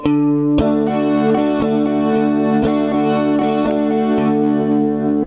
Le guitariste électrique - Exercice de rythmique 2
L'accord est Ré (xx0232).
rythme2.wav